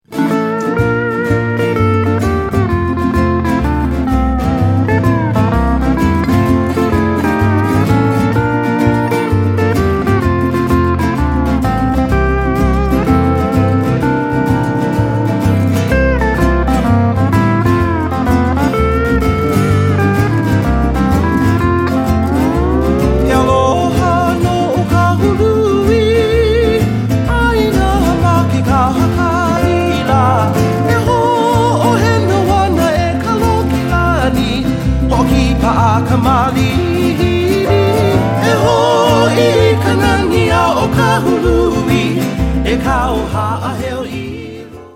Genre: Traditional Hawaiian.